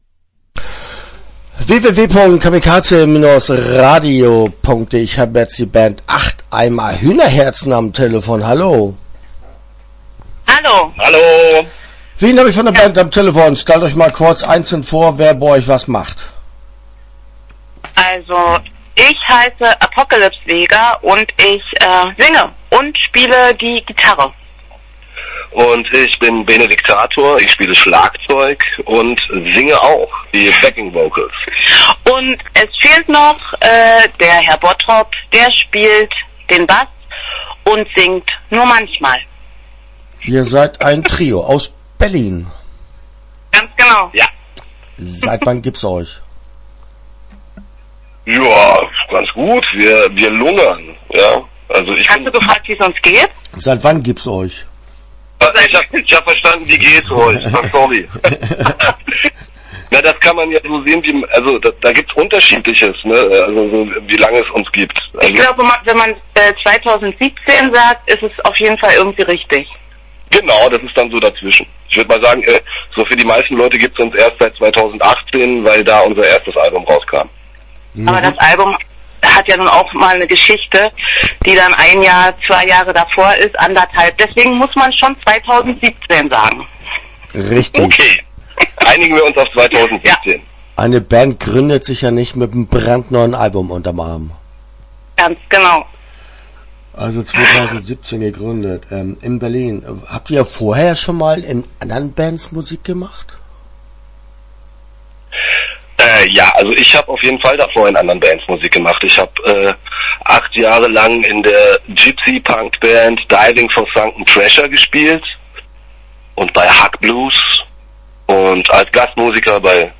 Start » Interviews » Acht Eimer Hühnerherzen